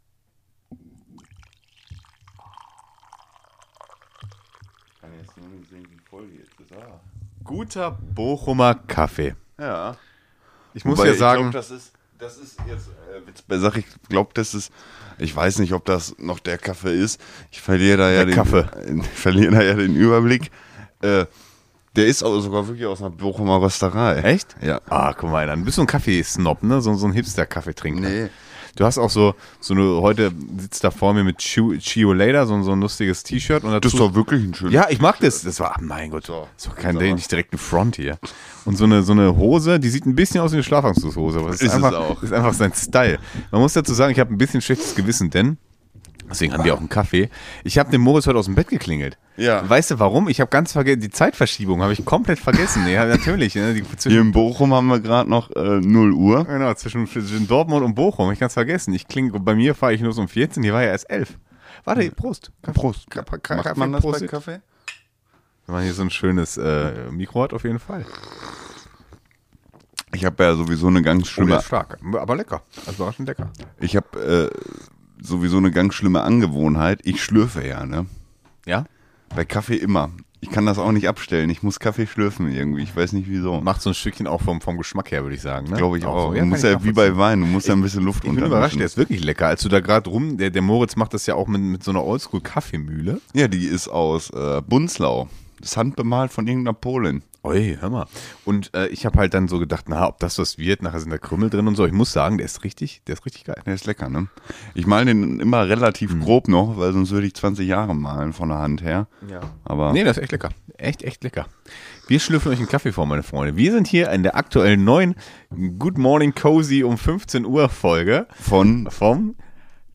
Locker aus der Hose heraus sitzen die beiden Quatschköpfe morgens um 14 Uhr bei einer guten Tasse Kaffee zusammen und sinnieren über ihren Alltag.